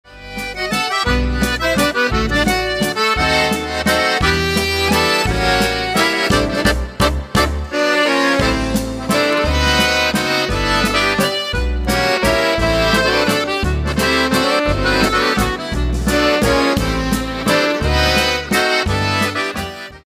A Milwaukee, Wisconsin Polka Band